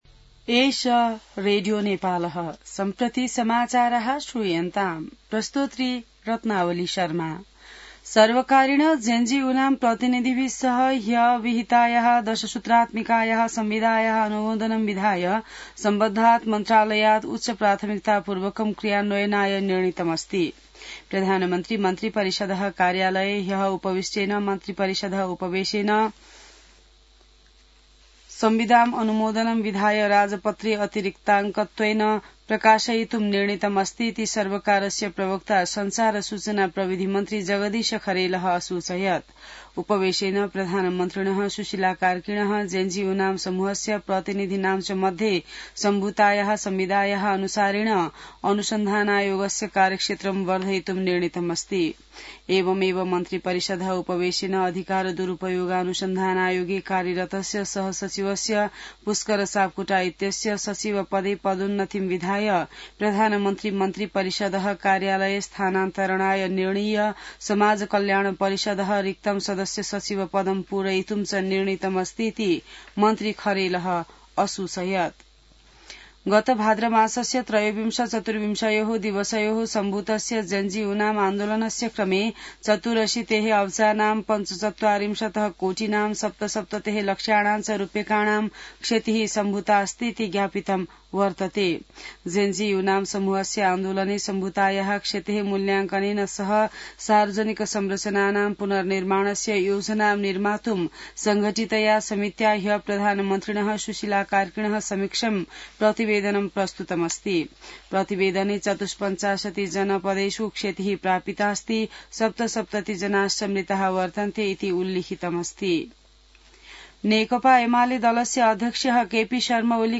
An online outlet of Nepal's national radio broadcaster
संस्कृत समाचार : २६ मंसिर , २०८२